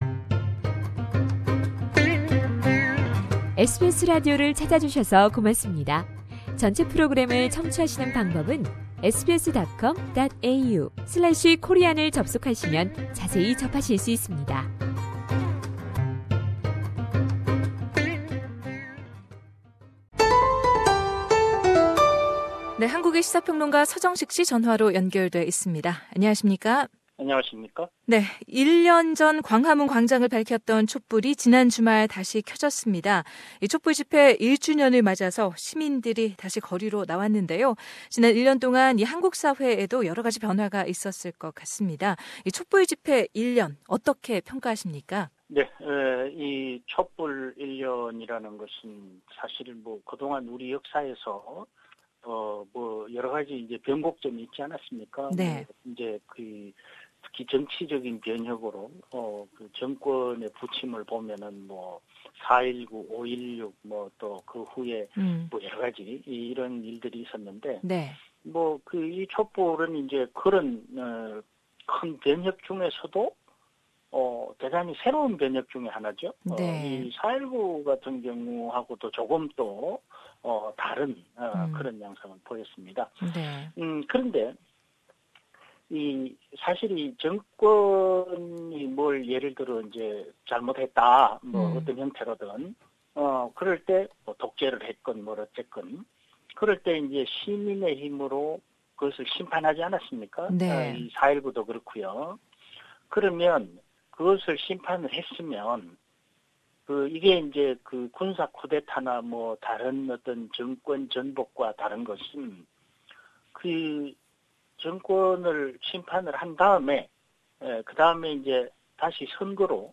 상단의 팟캐스트로 전체 인터뷰를 들으실 수 있습니다.